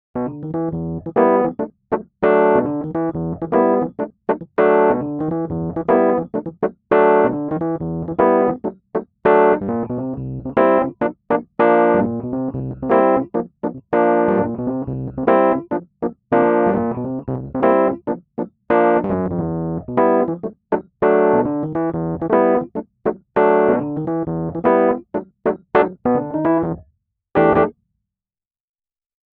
复古电钢琴 ETI Roads MKII KONTAKT-音频fun
ETI ROADS MKII 是一款免费的 Kontakt 库，它精心捕捉了 1980 年 Rhodes Mark II 电钢琴的声音。
- 踏板和乐器噪音
- 经典的 VIBRATO 效果，甚至可以在立体声模式下使用
- 放大器模拟，包括音箱